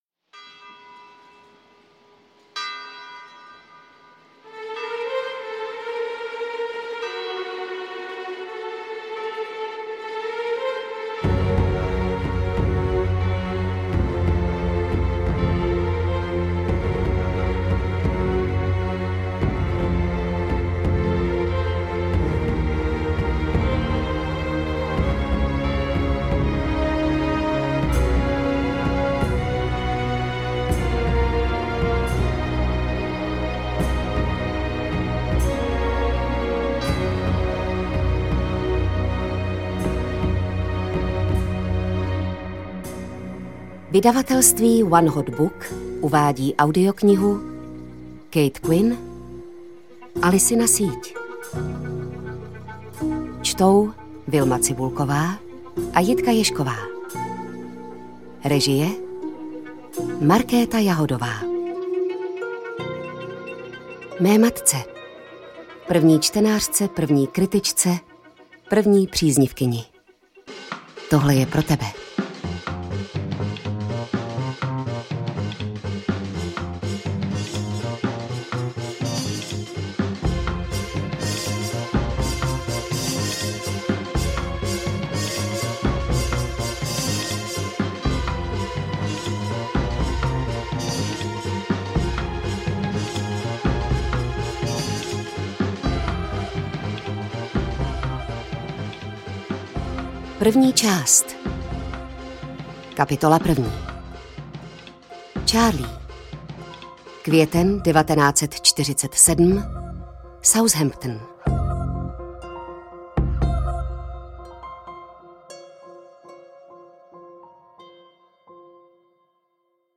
Interpreti:  Vilma Cibulková, Jitka Ježková
AudioKniha ke stažení, 48 x mp3, délka 20 hod. 2 min., velikost 1088,0 MB, česky